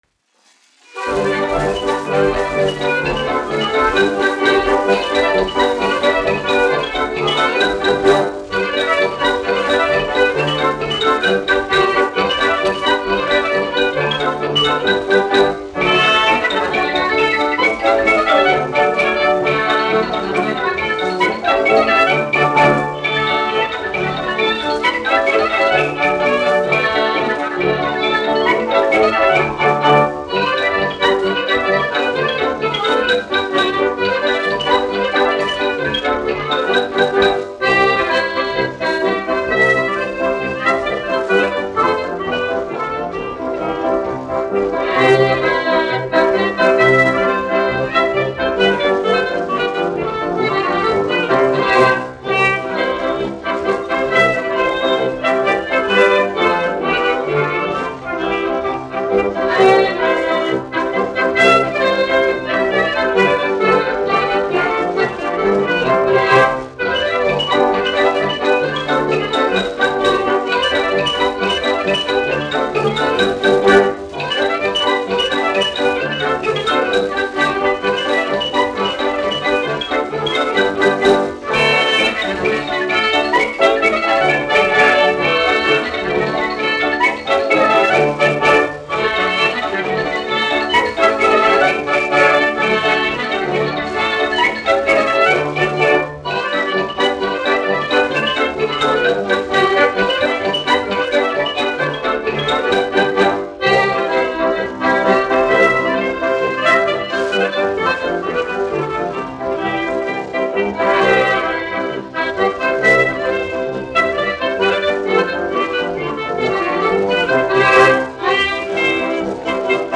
1 skpl. : analogs, 78 apgr/min, mono ; 25 cm
Polkas
Tautas deju mūzika -- Latvija
Skaņuplate